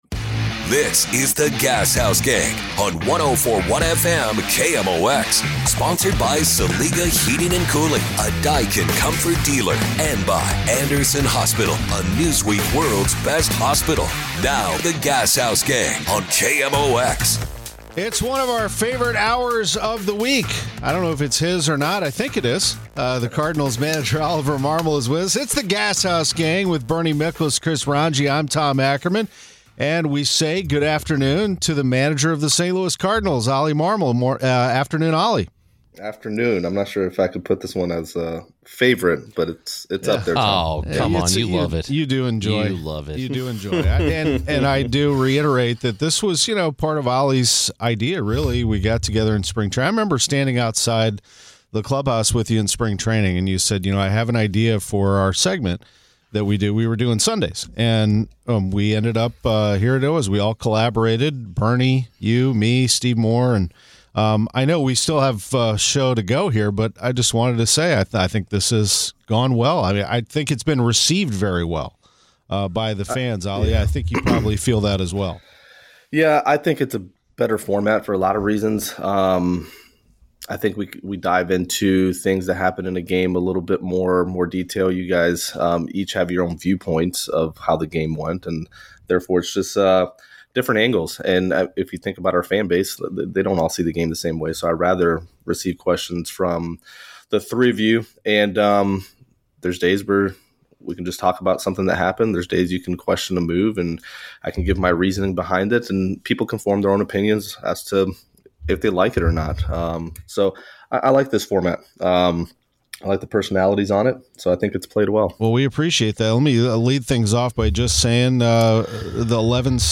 Gashouse Gang The Gashouse Gang – Oli Marmol on bullpen management, Gold Glove chances, and Arenado's return Play episode September 16 39 mins Bookmarks Episode Description Cardinals manager Oli Marmol joins The Gashouse Gang on KMOX as he does every Tuesday. In today’s conversation, Oli discusses how he manages the workload of the bullpen, Masyn Winn’s injury and chances of a Gold Glove at shortstop, and the return of Nolan Arenado.